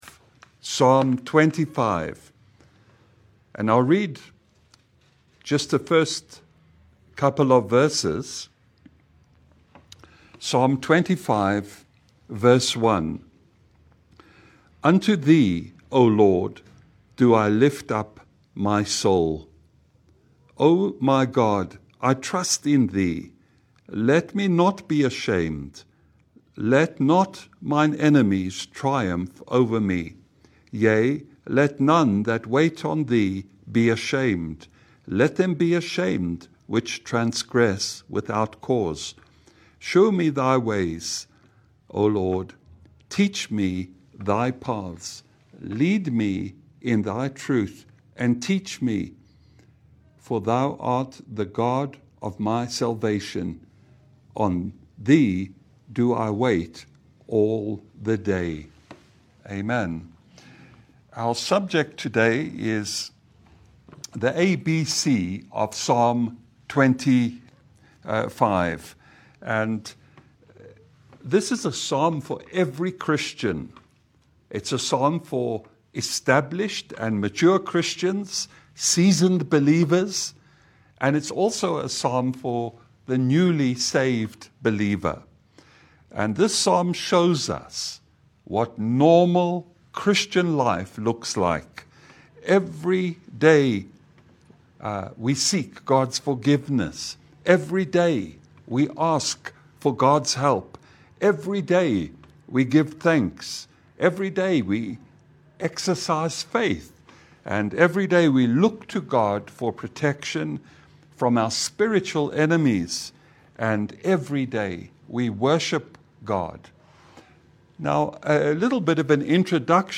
Service Type: Lunch hour Bible Study